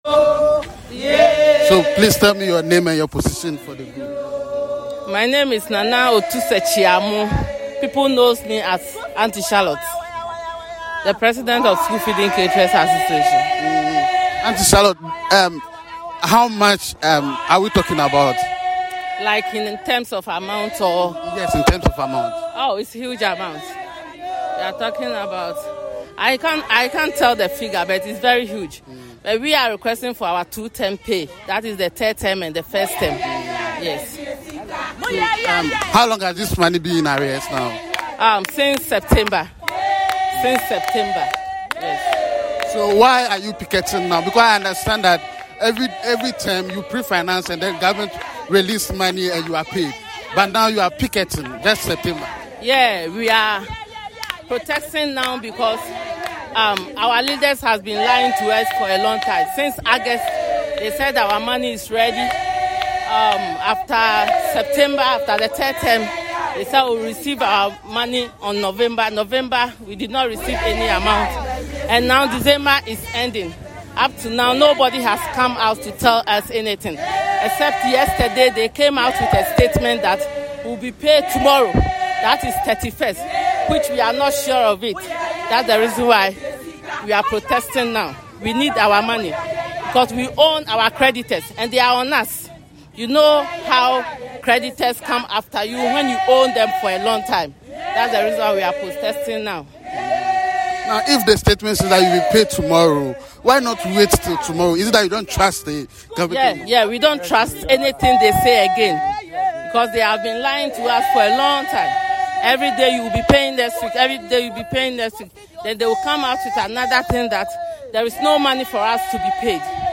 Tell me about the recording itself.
Caterers under the Ghana School Feeding Programme on Monday, December 30, staged a protest at the Ministry of Gender, Children, and Social Protection, demanding the payment of arrears owed for two academic terms—covering the third term of 2023 and the first term of 2024.